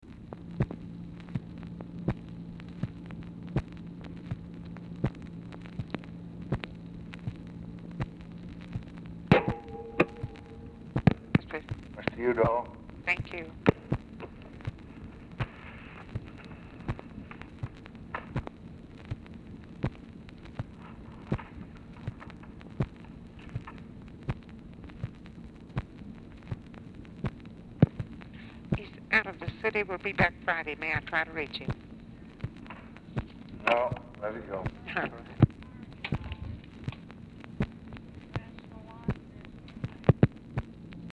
Telephone conversation # 2454, sound recording, LBJ and TELEPHONE OPERATOR, 3/10/1964, time unknown | Discover LBJ
Format Dictation belt
Specific Item Type Telephone conversation